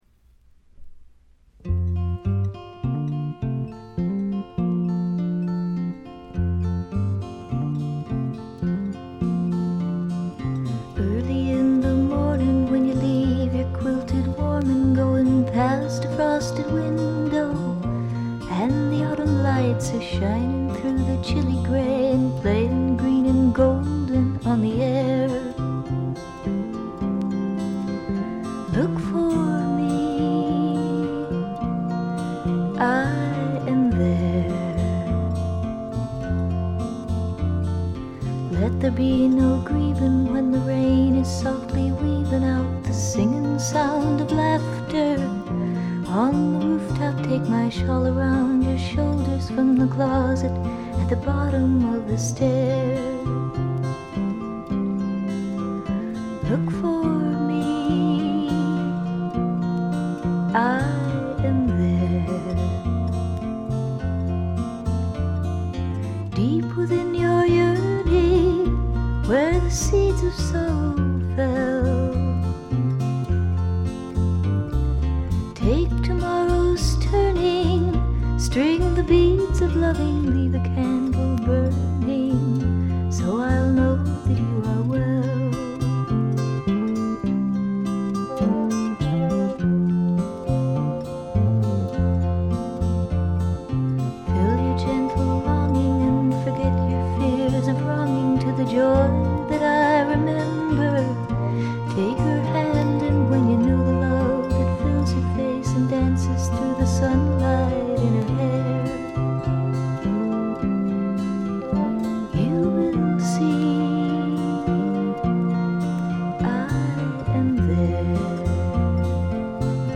ほとんどノイズ感無し。
ルックスよりも少しロリ寄りの声で、ちょっとけだるくてダークでたまらない魅力をかもしだしています。
試聴曲は現品からの取り込み音源です。
Recorded at T.T.G. Studios, Hollywood